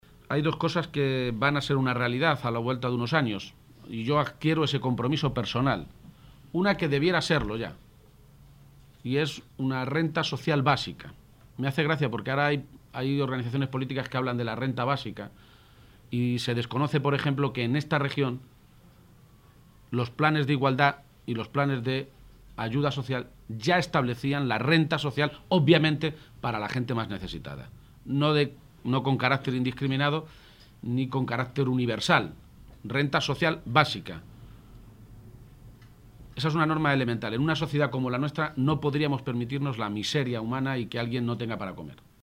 García-Page se pronunciaba de esta manera esta mañana, en Toledo, en declaraciones a los medios de comunicación durante la firma de un convenio con Cruz Roja.
Cortes de audio de la rueda de prensa